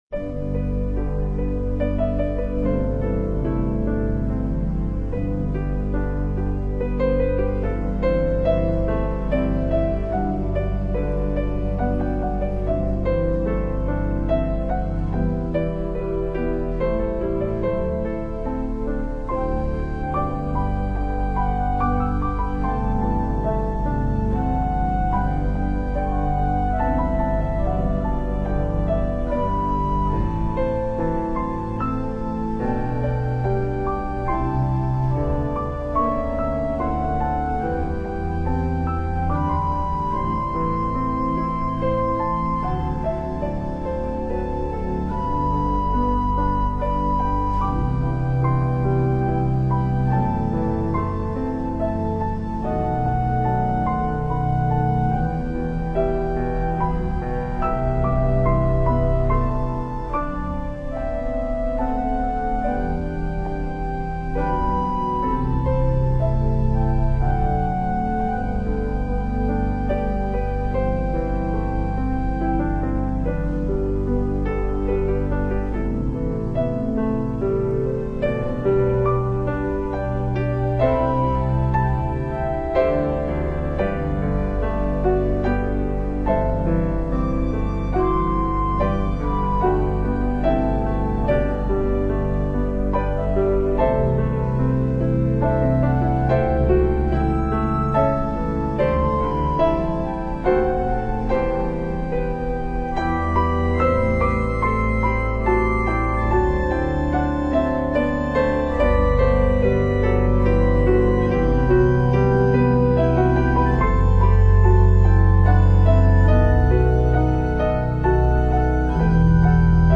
Voicing: Piano and Organ